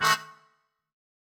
GS_MuteHorn-Adim.wav